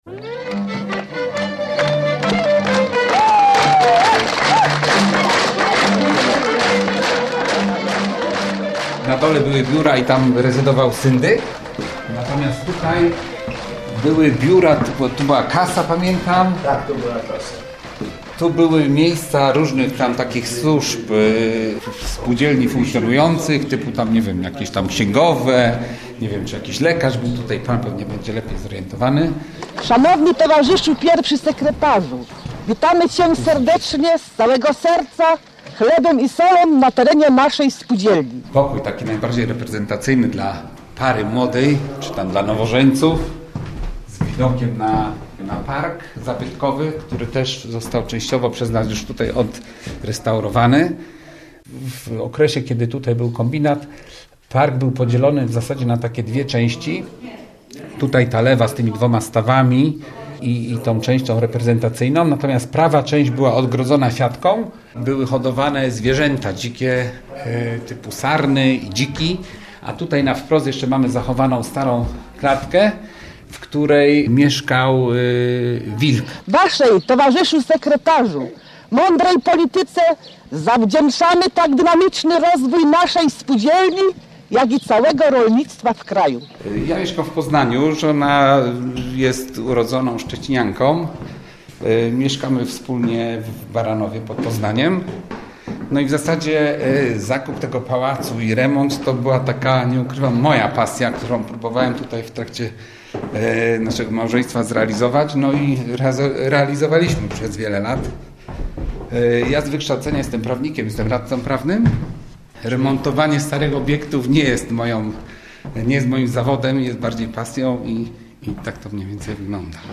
Kombinat - reportaż